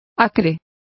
Complete with pronunciation of the translation of pungent.